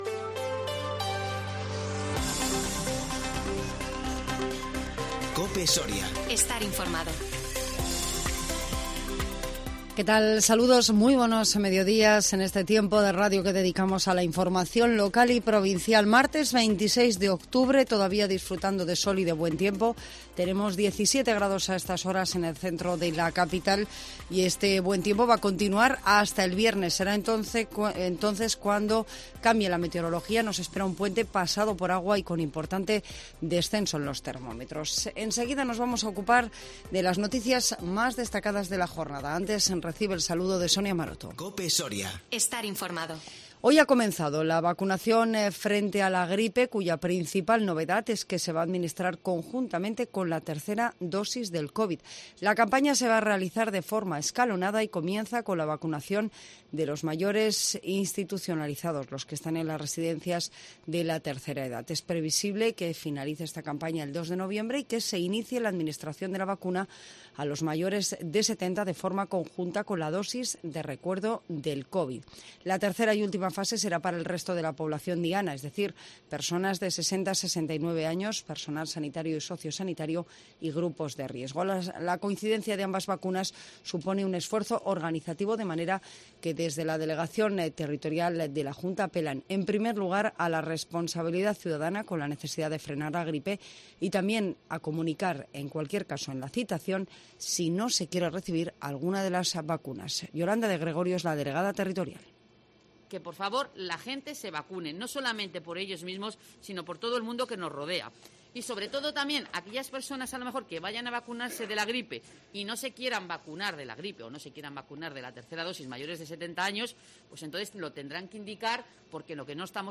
INFORMATIVO MEDIODÍA 26 OCTUBRE 2021